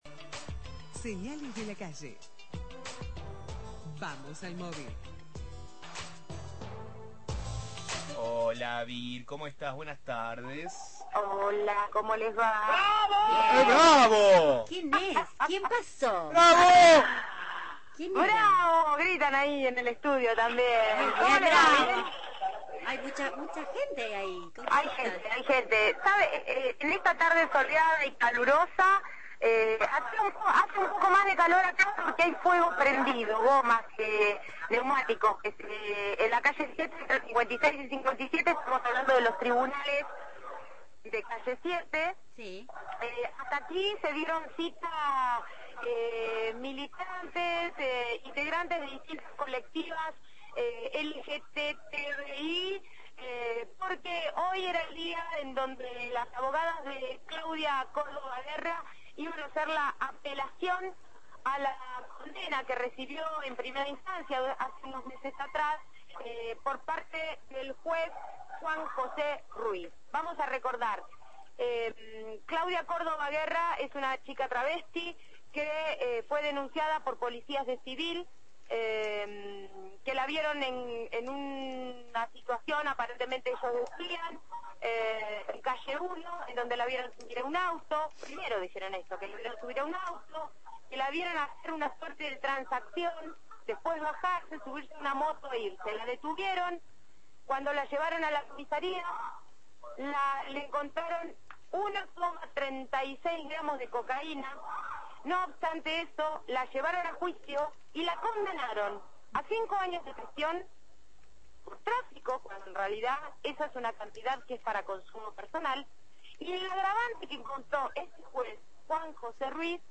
Móvil/ Audiencia judicial